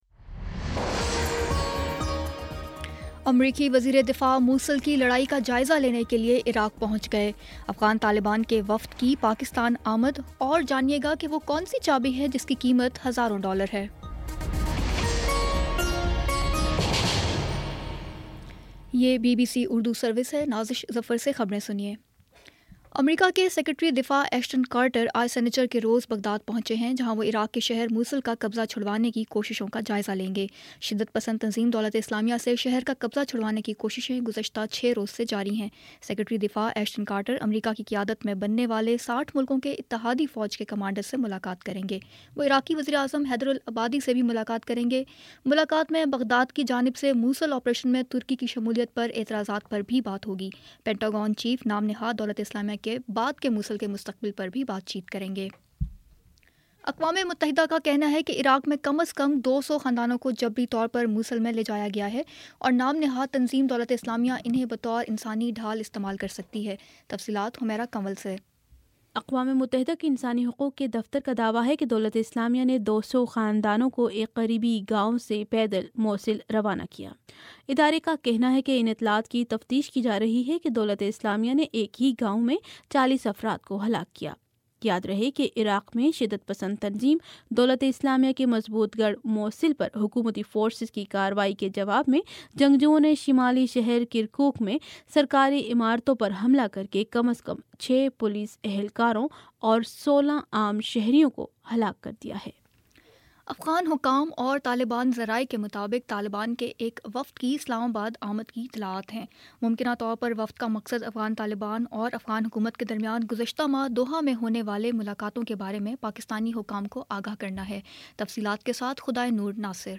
اکتوبر 22 : شام چھ بجے کا نیوز بُلیٹن